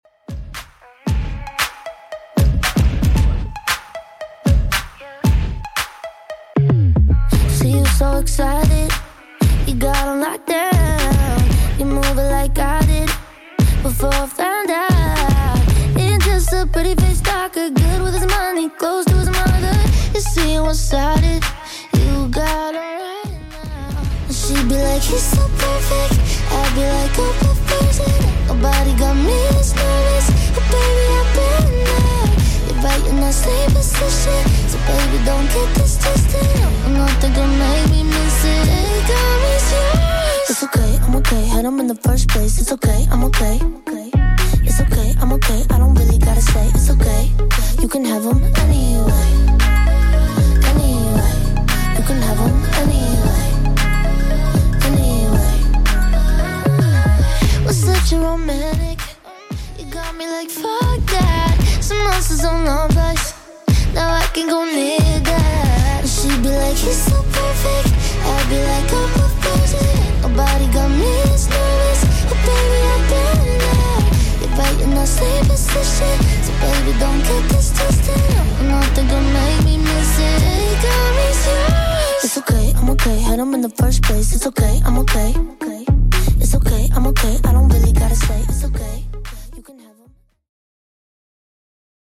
Genre: 80's
BPM: 118